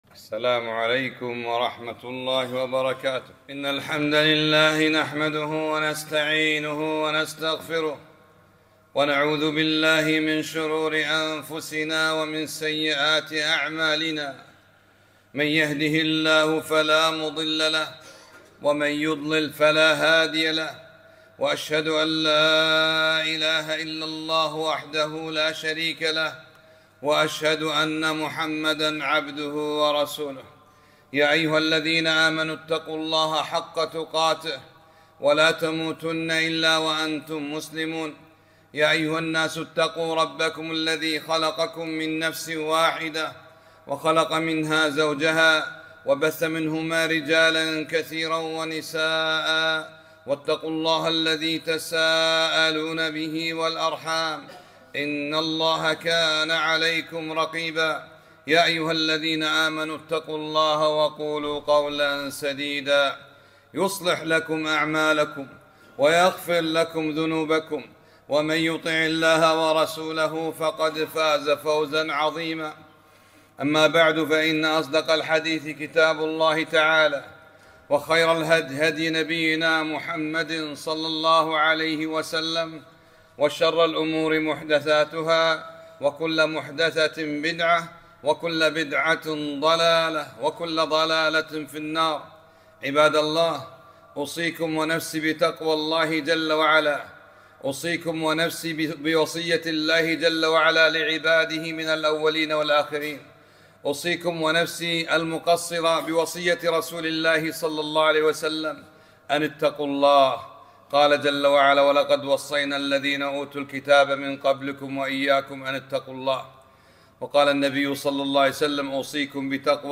خطبة - أعظم آية في كتاب الله